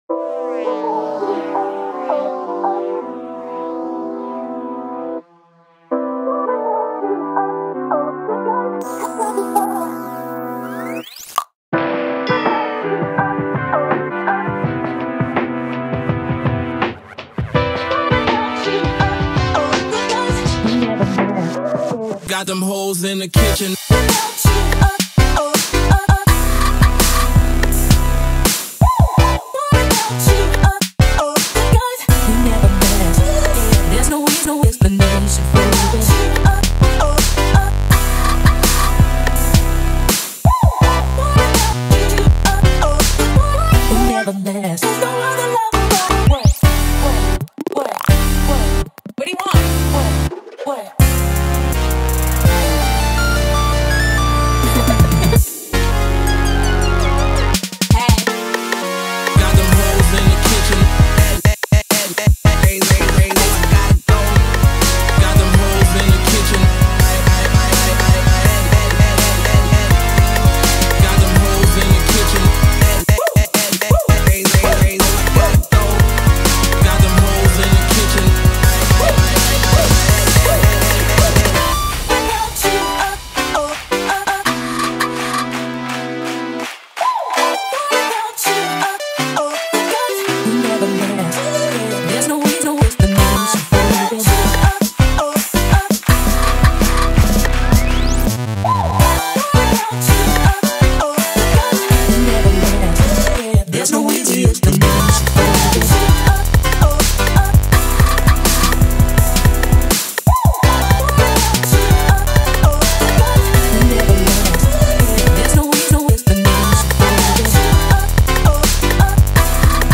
BPM83-165